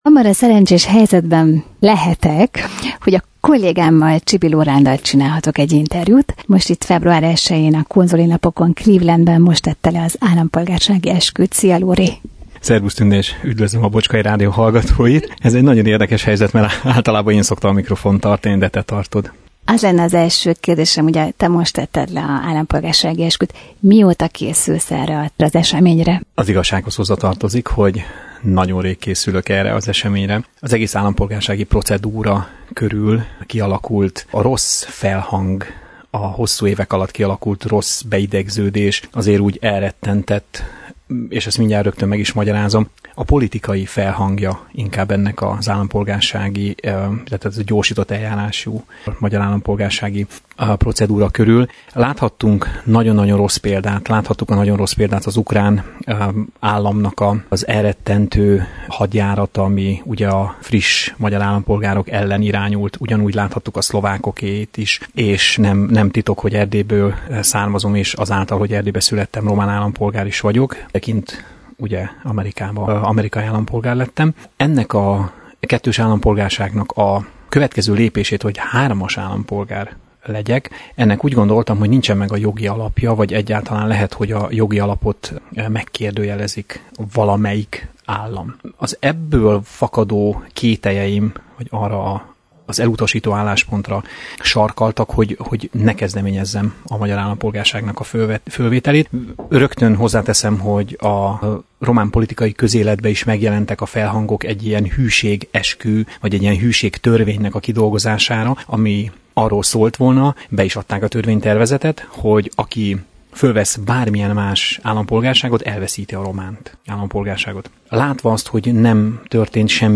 Érdekes helyzet, amikor kolléga interjúol kollégát.